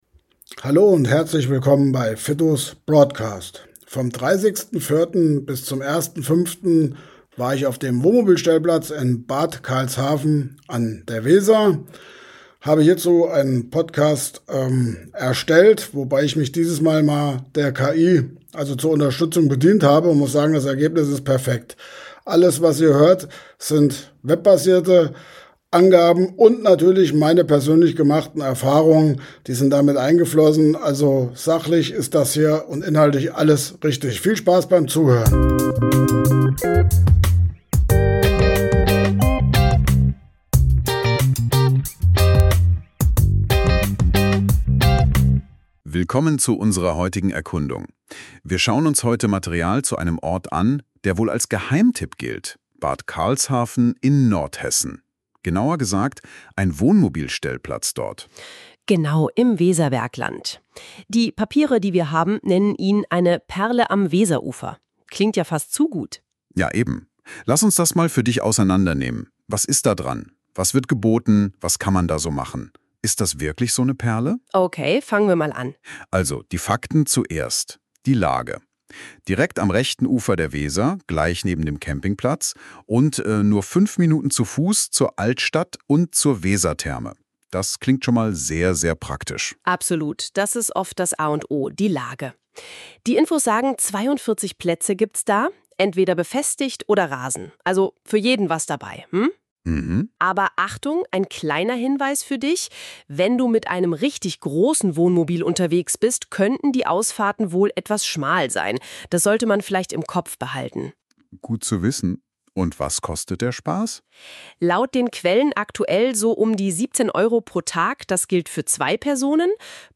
Dieser Podcast wurde mit KI generiert, die Fakten sind von mir persönlich erlebt.